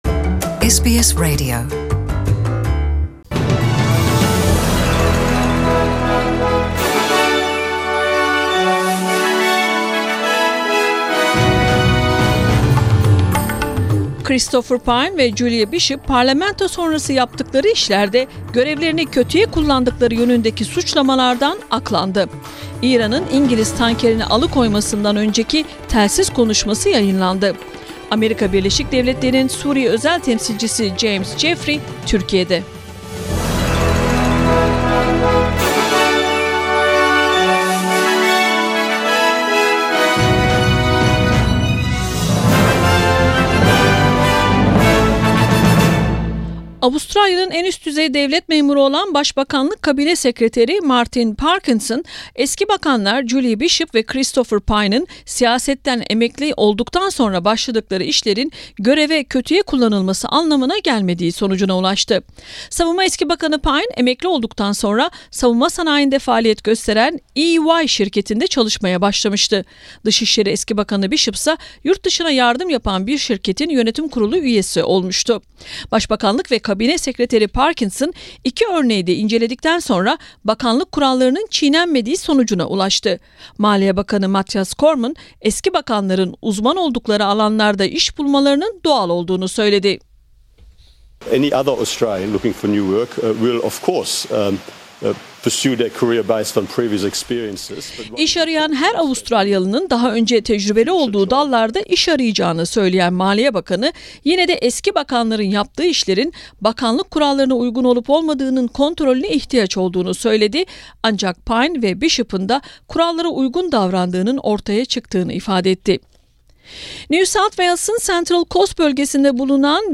SBS Radyosu Türkçe Programı'ndan Avustralya, Türkiye ve dünyadan haberler.